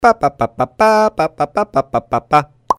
веселые
смешные
голосовые